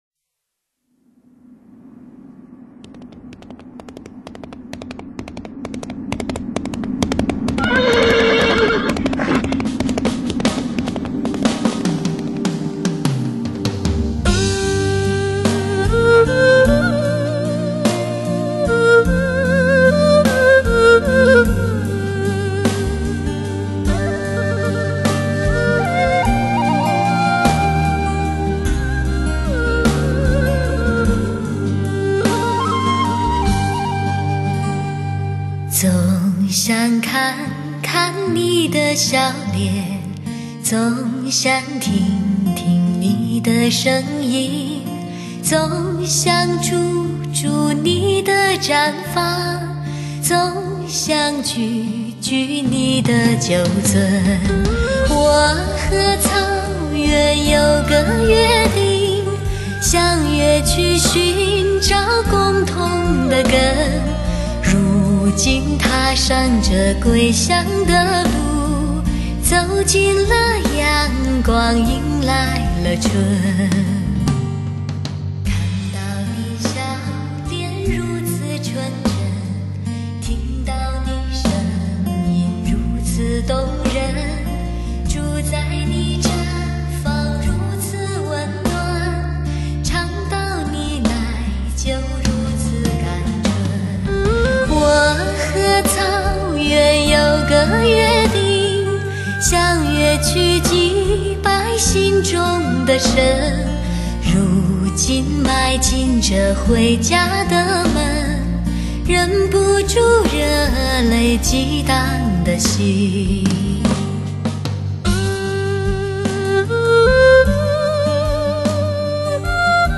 完美环绕+最新科技DTS-ES 6.1 CD，还原最真实的HI-FI声场效果，营造最感人的真情氛围